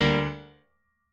admin-leaf-alice-in-misanthrope/piano34_9_006.ogg at main